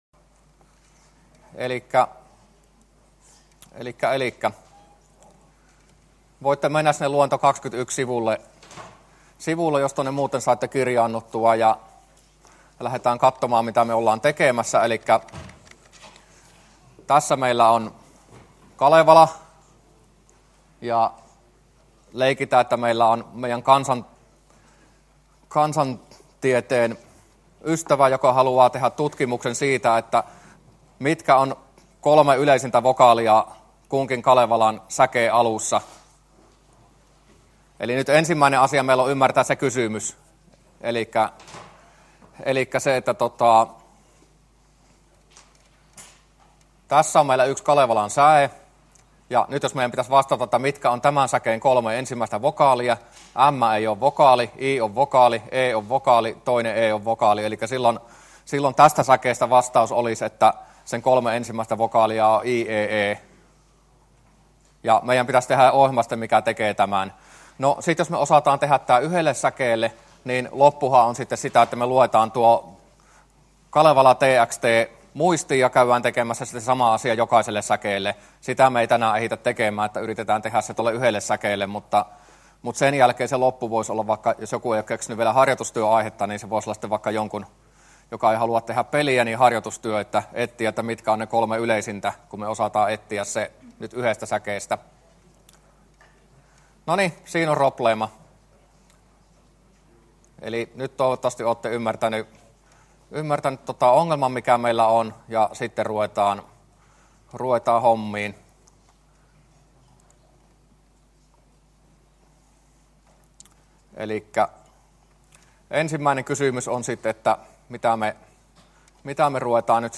luento21